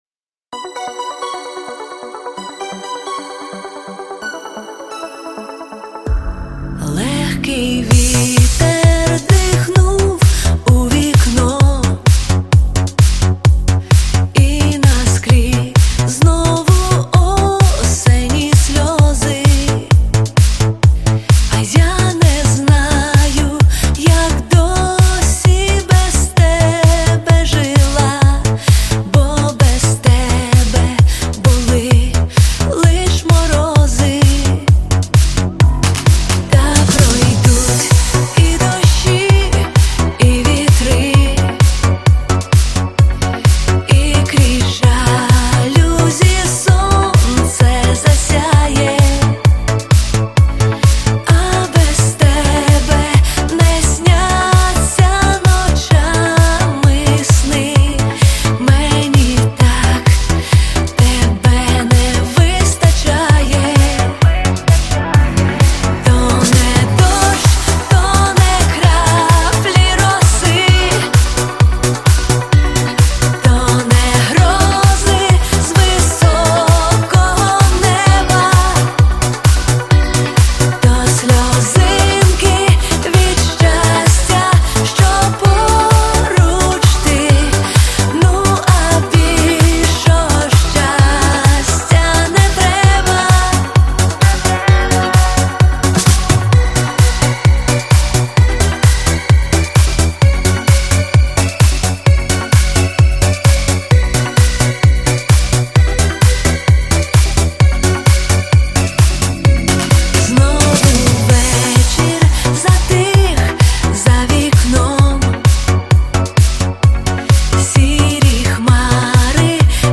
позитивні і легкі пісні